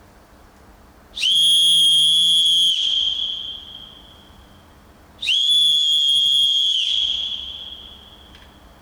Four internal chambers gives a loud, clear sound and the two slightly different tones emanating from the two sides of the whistle are effective. The harder you blow, the louder the sound.
Fox 40 Sharx whistle